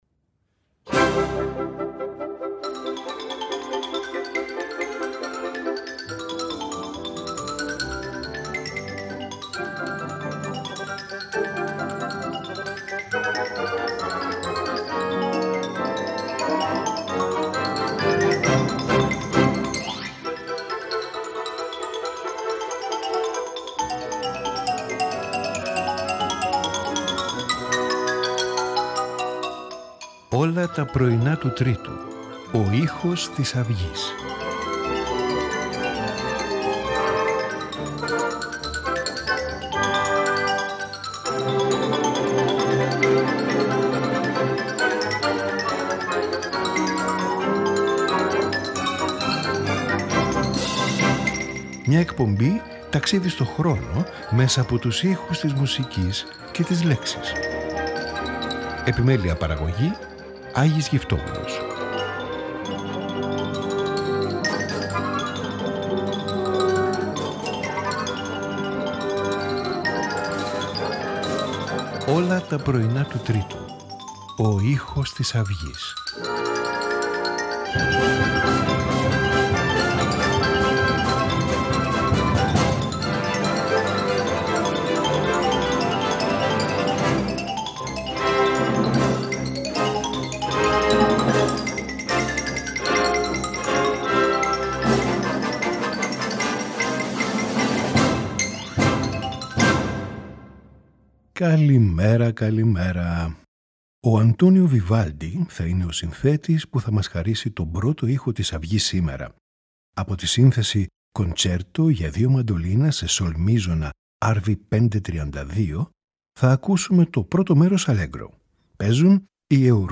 Concerto for Two Mandolins in G major
Violin Concerto in E minor